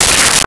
Space Gun 12
Space Gun 12.wav